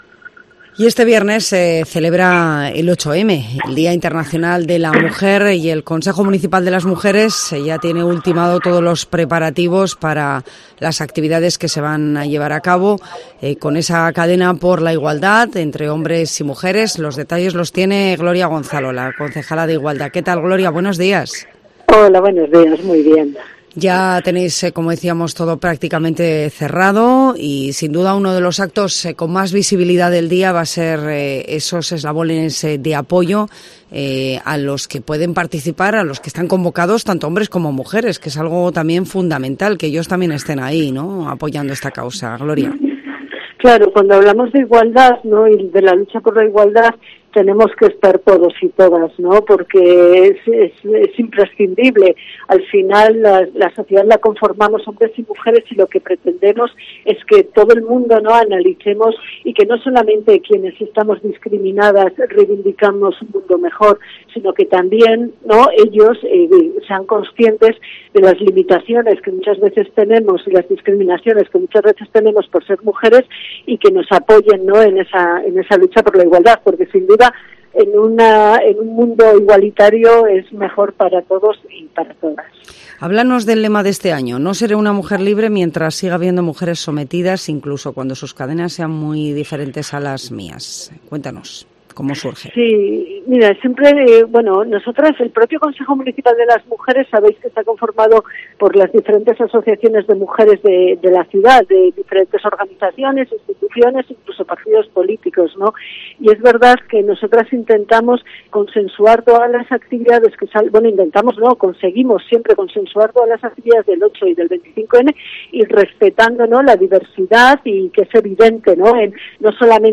12:26 1 min lectura Descargar Facebook Twitter Whatsapp Telegram Enviar por email Copiar enlace La concejala de Igualdad, Gloria Gonzalo, detalla en COPE Soria los actos del 8M organizados por el Consejo Municipal de las Mujeres en la capital soriana.